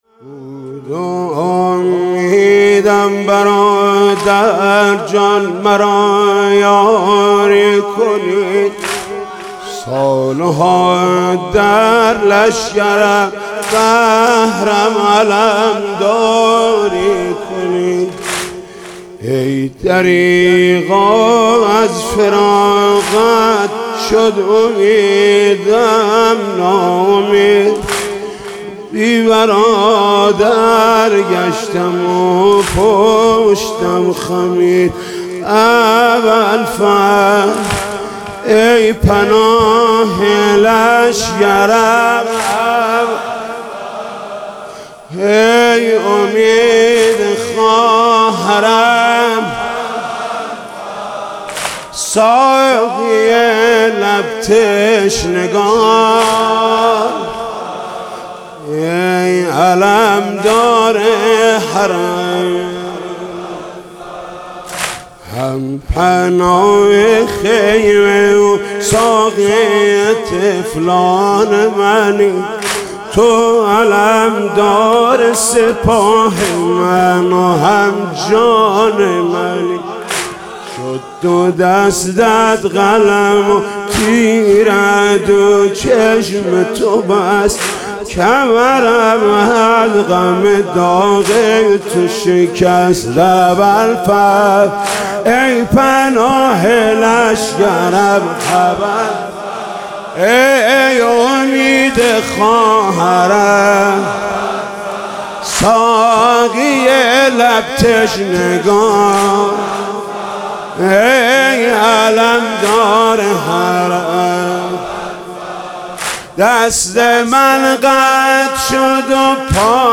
دانلود مداحی بود اميدم تا مرا يارى كنى/ محمود کریمی شب عاشورا محرم96